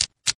TakeCard.mp3